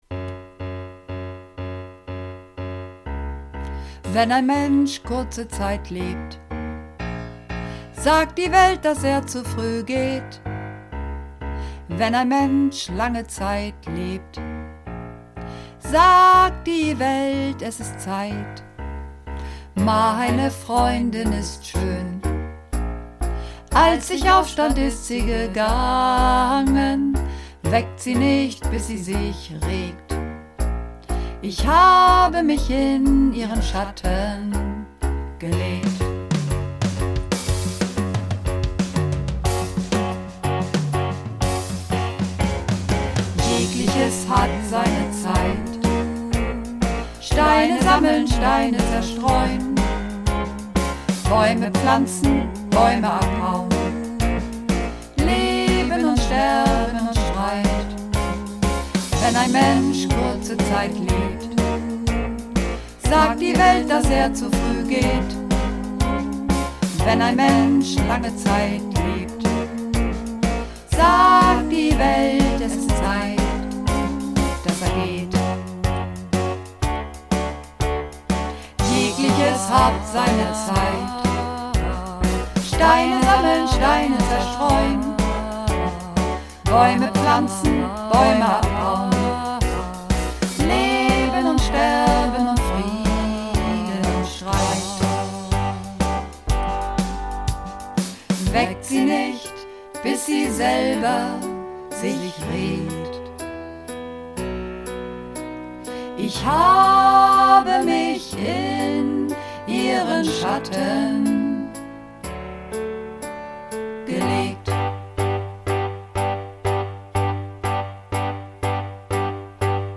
Mehrstimmig